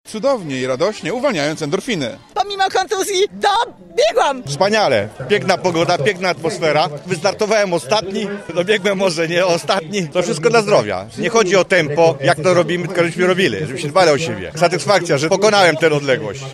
sonda-City-Trail.mp3